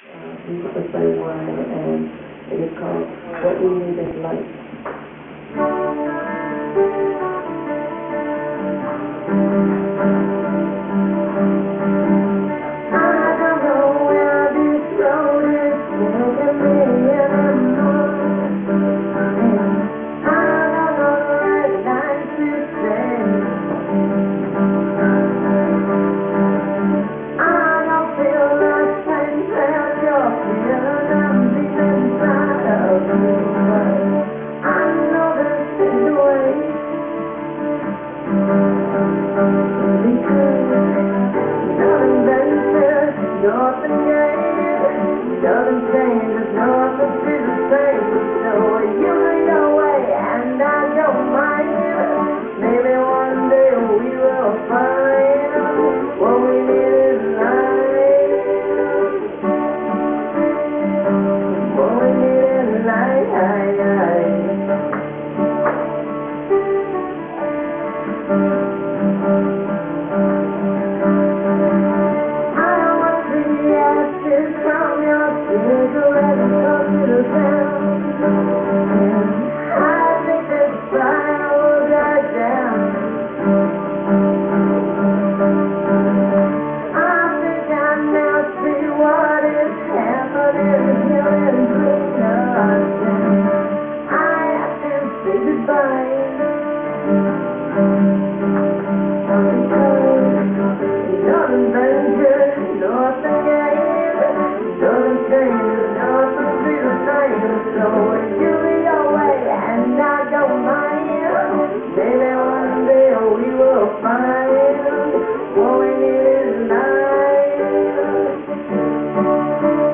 live at The Cafe 11/04/03: